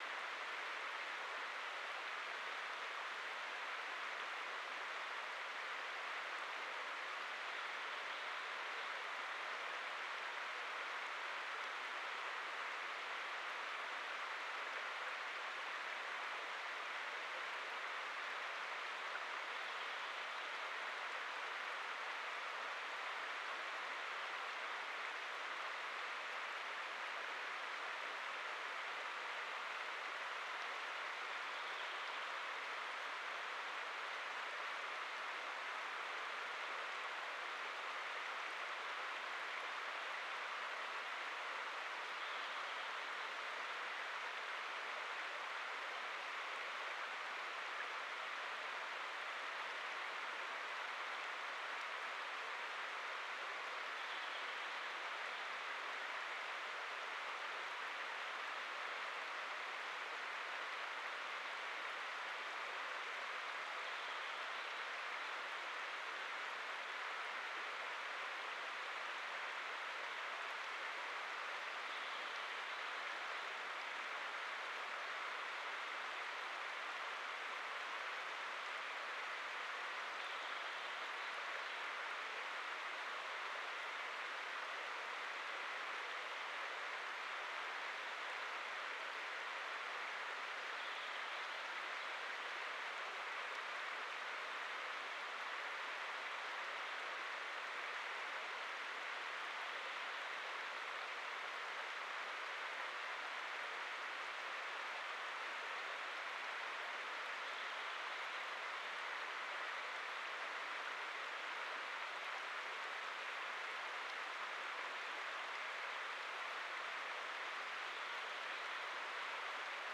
Quellrauschen in Bänder geteilt 2000.wav